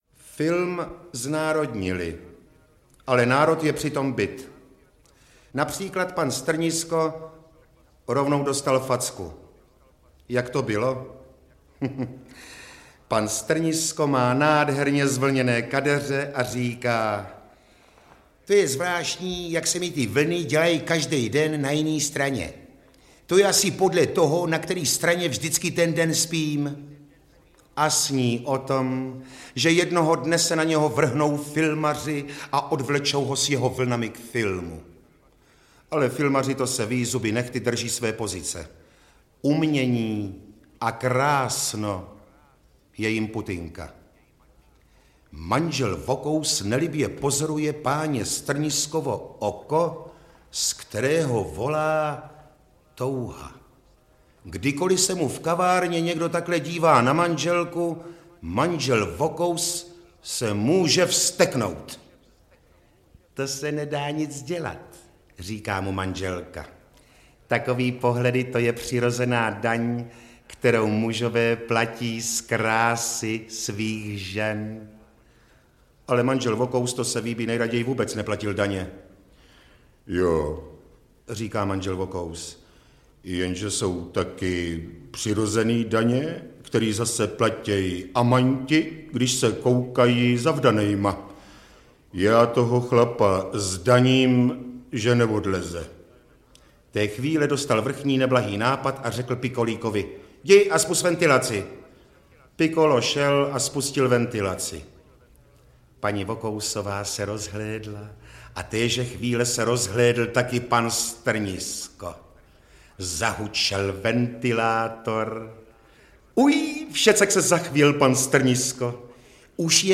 Supraphon vypravuje...2 (Němec, Hašek, Neruda, Čapek, Haas, Rada) - František Němec - Audiokniha
• Čte: Jan Pivec